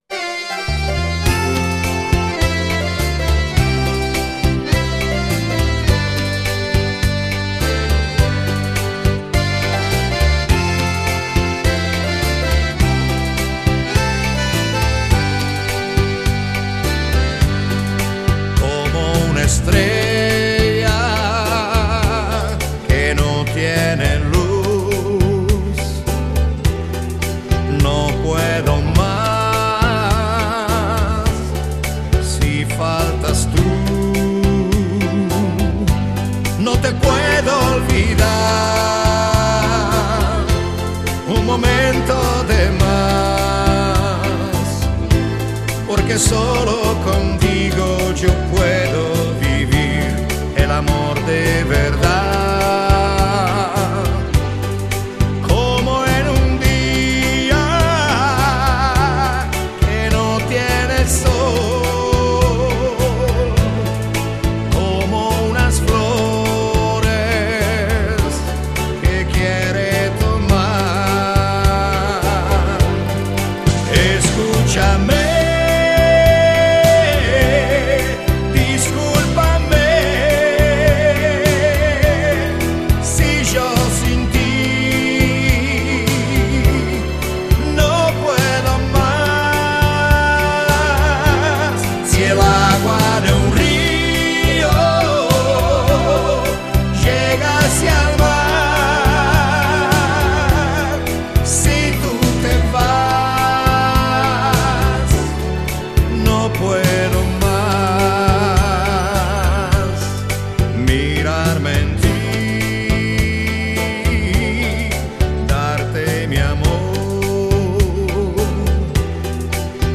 Genere: Bachata